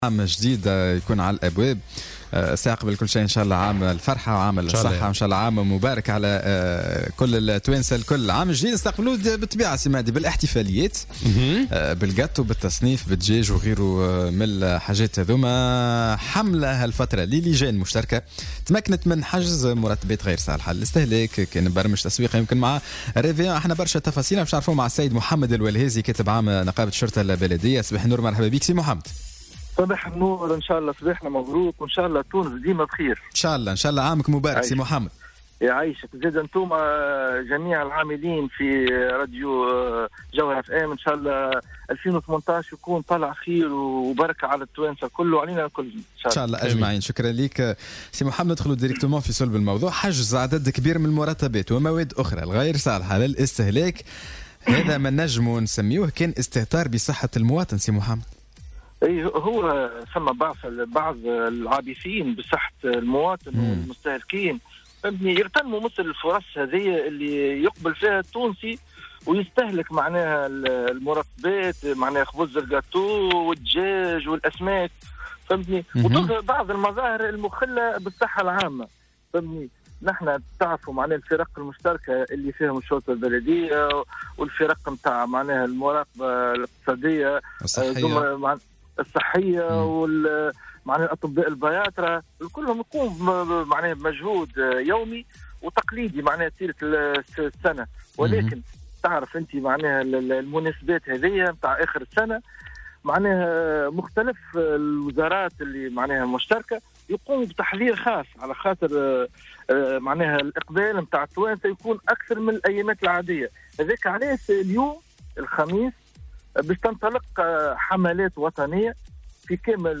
وأوضح في برنامج "صباح الورد" على "الجوهرة اف أم" ان الحملة تستهد محلات بيع "اللحوم البيضاء" وأيضا الاسماك والمحلات المفتوحة للعموم بشكل عام، بحسب تعبيره.